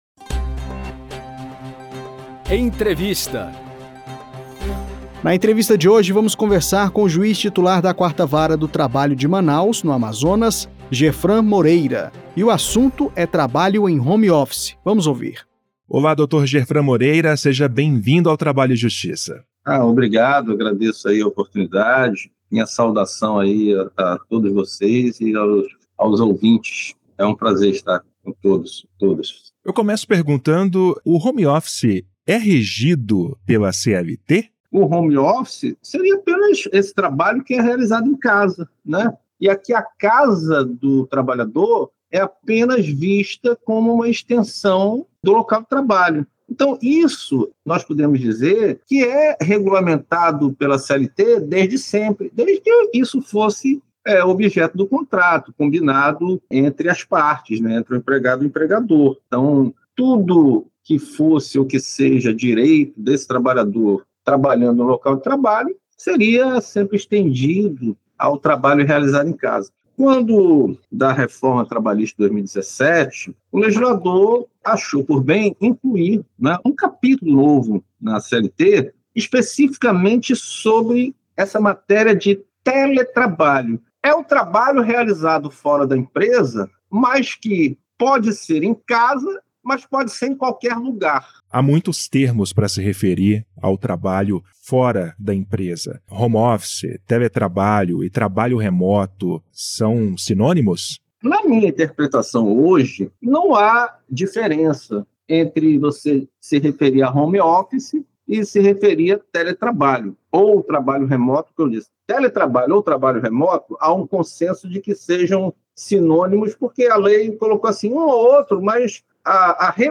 Em entrevista à Rádio TST, juiz do trabalho explica os limites do controle patronal sobre o teletrabalho